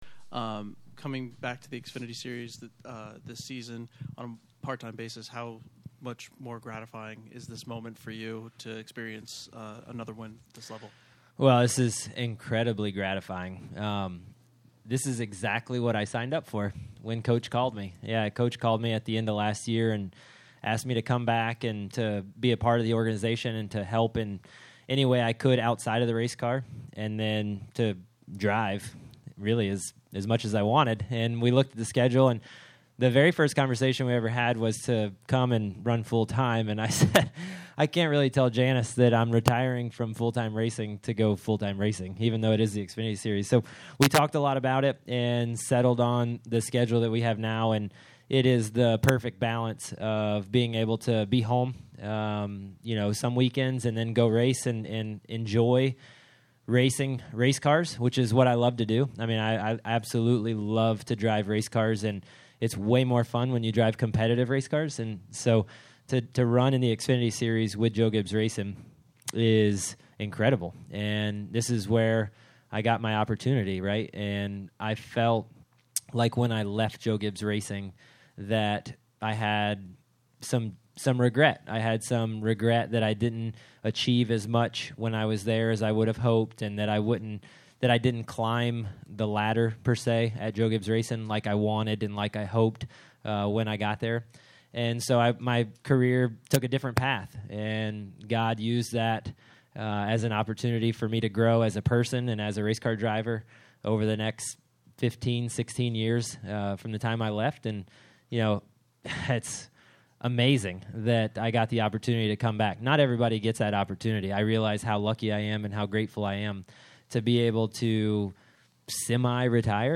Interview: